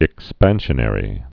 (ĭk-spănshə-nĕrē)